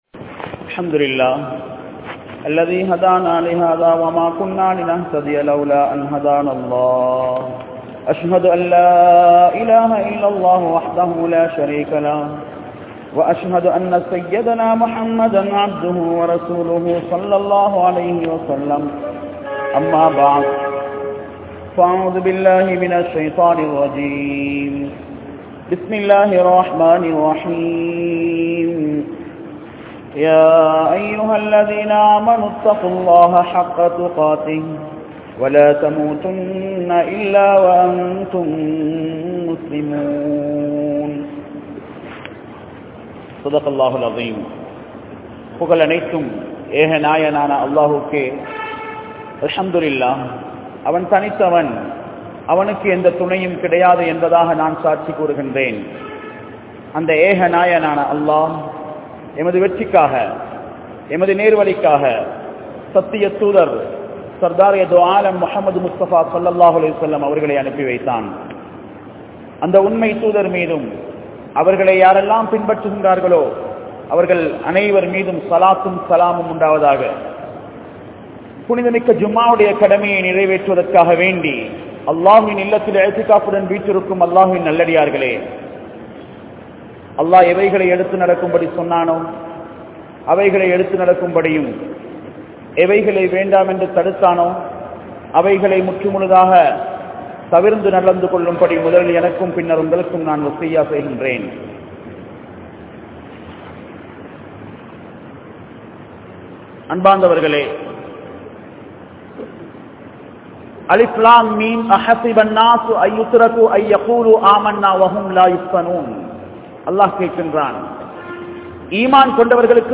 Islaathai Alikka Mudiyaathu | Audio Bayans | All Ceylon Muslim Youth Community | Addalaichenai